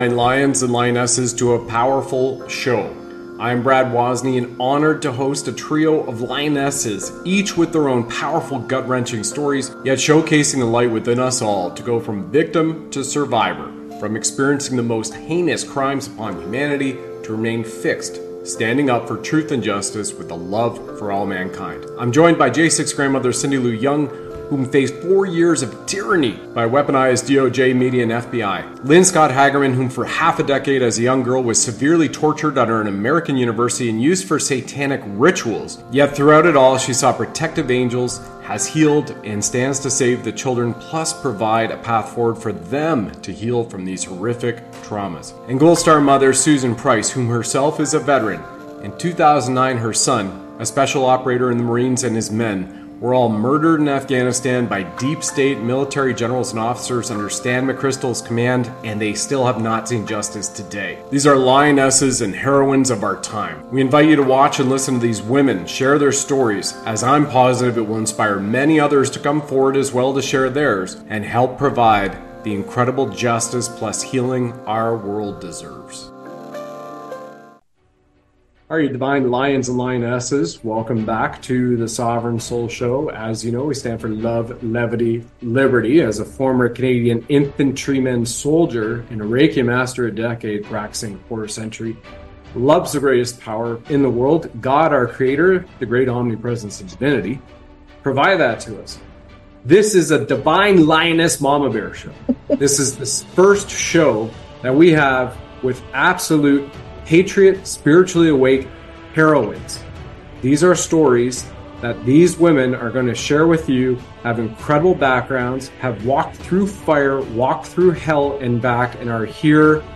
three inspiring women who share their powerful stories of survival.